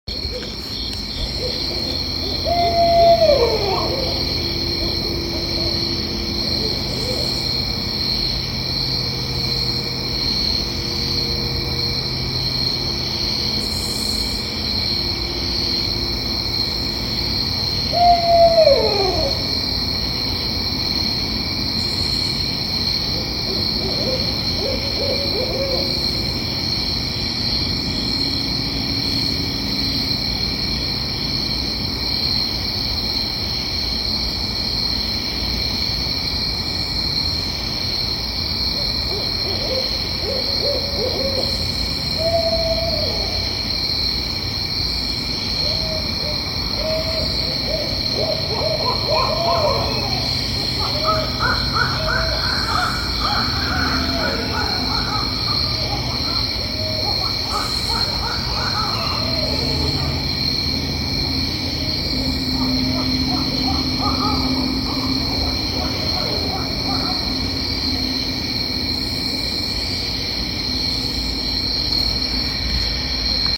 As I watched the presidential debate last night, the barred owls in the woods were hootin' at each other.
From 00:48 to 00:60 you can hear at least three of them at the same time.